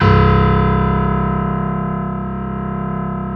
55p-pno01-C0.wav